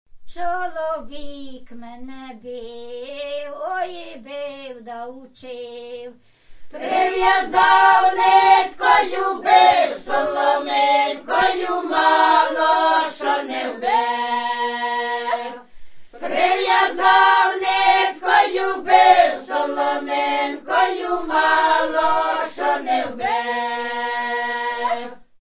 Традиційні пісні Правобережної Київщини
жартівлива